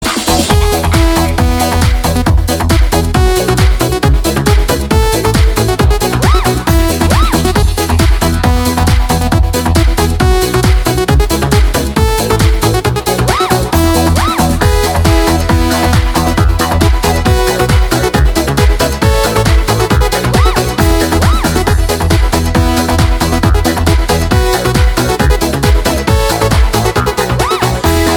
• Качество: 237, Stereo
dance
без слов
Заводная музычка без слов